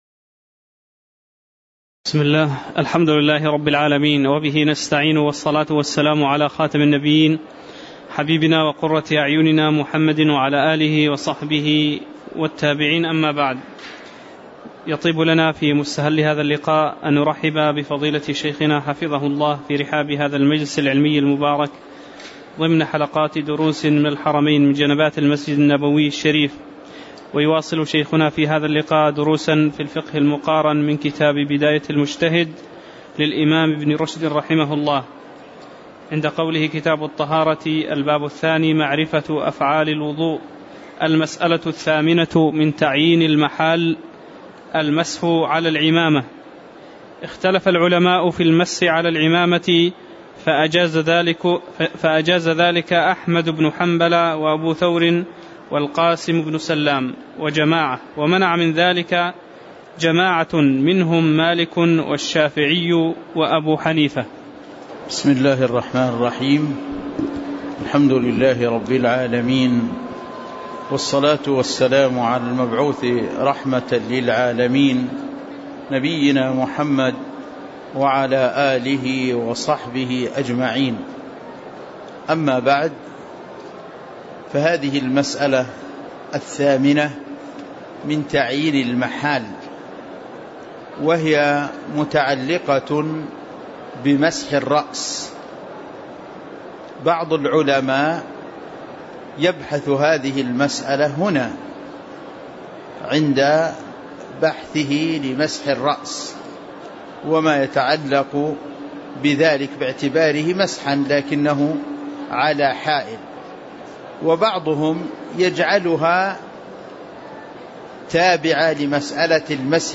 تاريخ النشر ١ رجب ١٤٣٩ هـ المكان: المسجد النبوي الشيخ